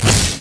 weapon_lightning_005_cut.wav